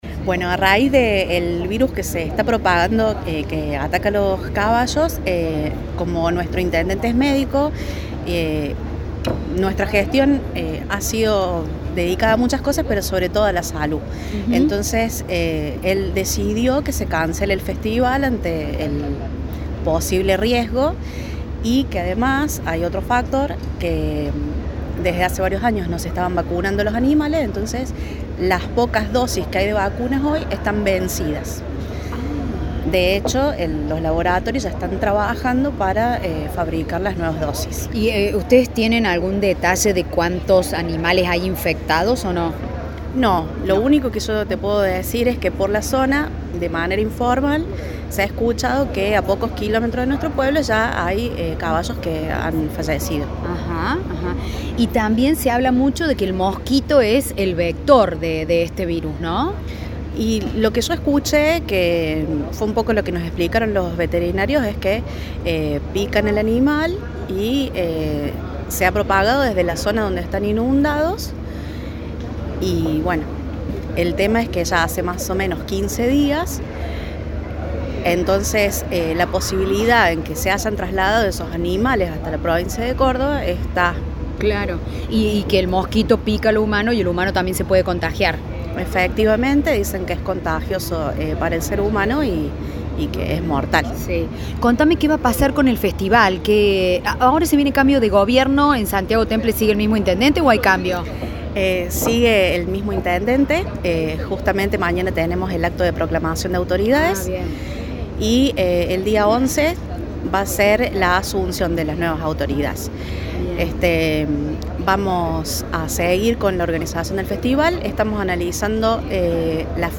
Carolina Mondino, secretaria de Cultura de Santiago Temple
Caro-Mondino-Secretaria-de-Cultura-de-Santiago-Temple.mp3